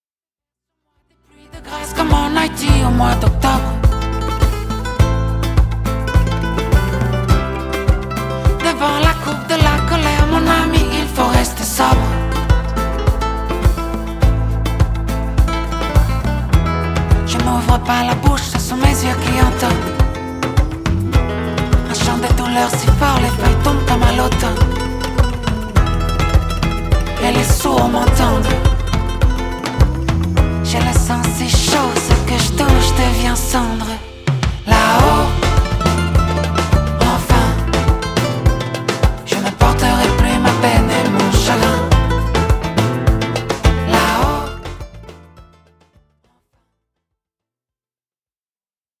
reggae roots